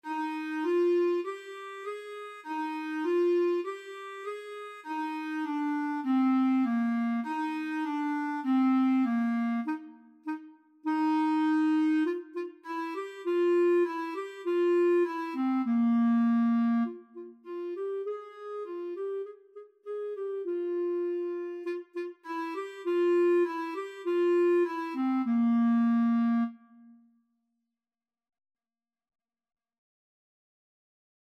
4/4 (View more 4/4 Music)
Bb4-Ab5
Clarinet  (View more Beginners Clarinet Music)
Classical (View more Classical Clarinet Music)